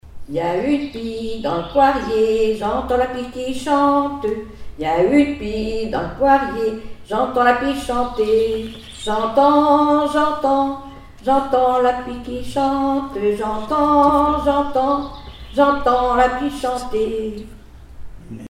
Mémoires et Patrimoines vivants - RaddO est une base de données d'archives iconographiques et sonores.
Couplets à danser
enfantine : berceuse
Comptines et formulettes enfantines